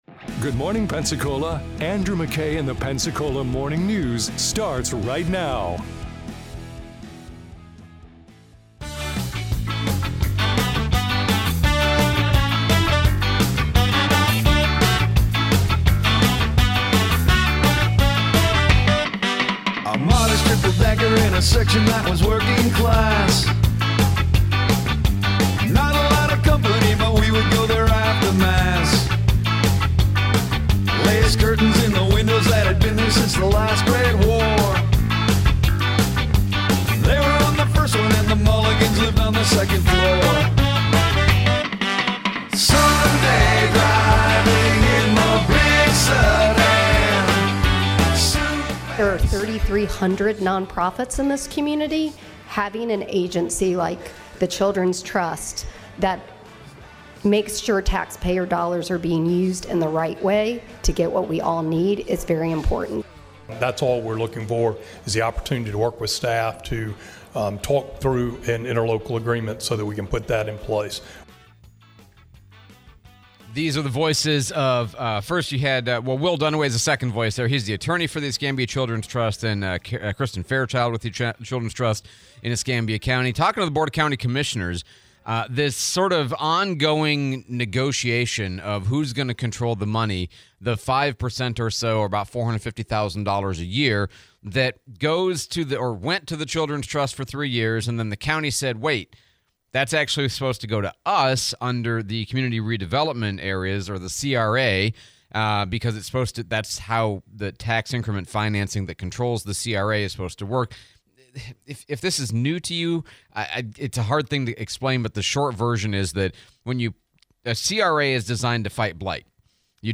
CRA allocation and the Children's Trust / Interview